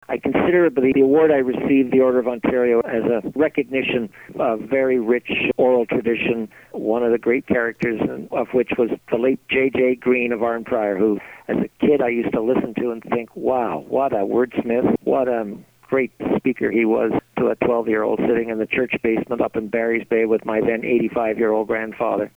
Sean Conway Order of Ontario interview